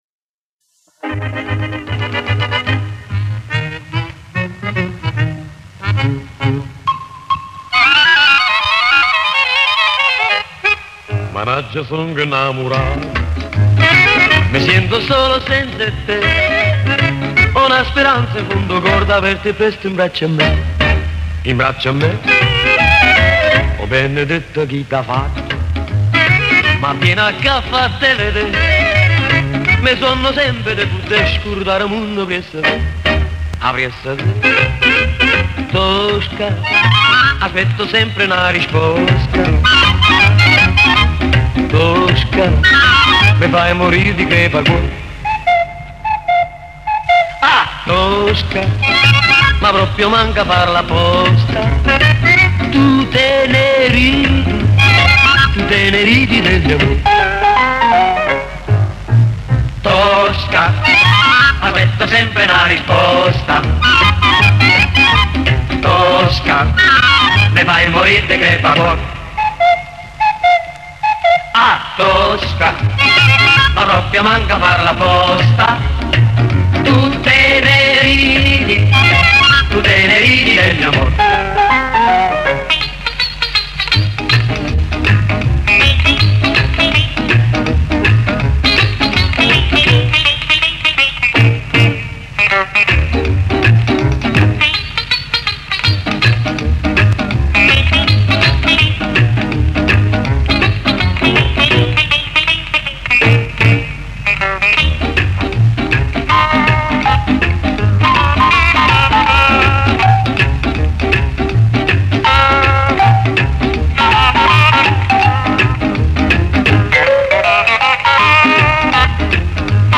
Поет итальянец, даже наполетанец. Акцент явно их.